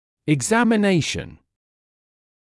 [ɪgˌzæmɪ’neɪʃn][игˌзэми’нэйшн]осмотр, обследование; экзамен